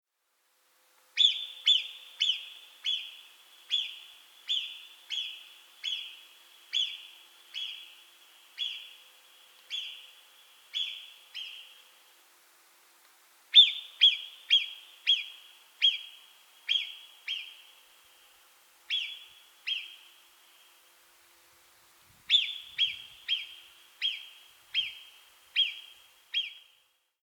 Hören Sie sich hier den Warnpfiff der Murmeltiere an. Warnpfiff vom Murmeltier Artenbeschreibung: Basisinformation Mehr lesen zum Thema:
Murmeltier__AMPLE_D12T259.mp3